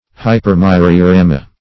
Search Result for " hypermyriorama" : The Collaborative International Dictionary of English v.0.48: Hypermyriorama \Hy`per*myr`i*o*ra"ma\, n. [NL., fr. Gr.